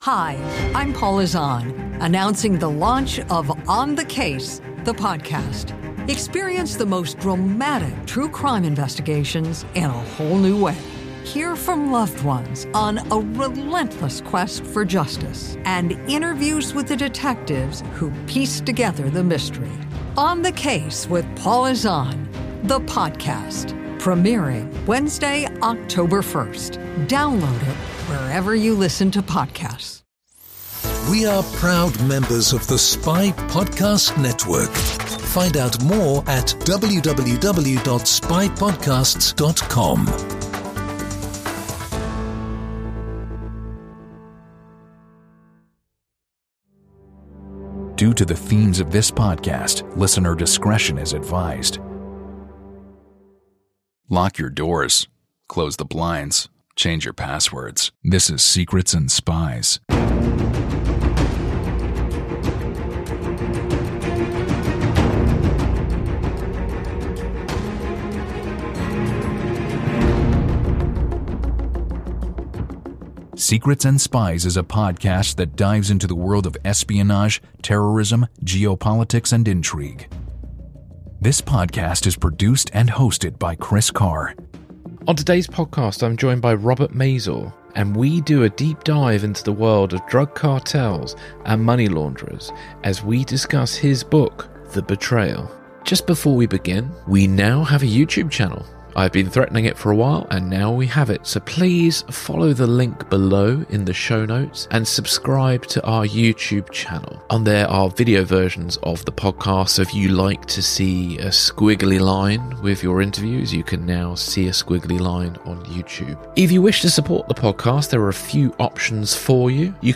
On this podcast, I am joined by Robert Mazur, and we discuss his book “The Betrayal”, which looks at his time working undercover against the Cali cartel for the DEA.